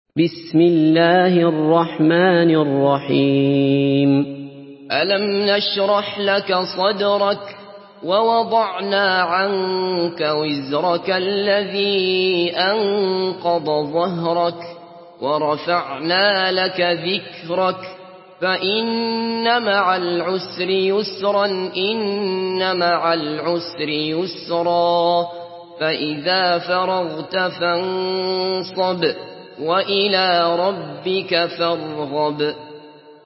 Surah Inşirah MP3 in the Voice of Abdullah Basfar in Hafs Narration
Surah Inşirah MP3 by Abdullah Basfar in Hafs An Asim narration.
Murattal Hafs An Asim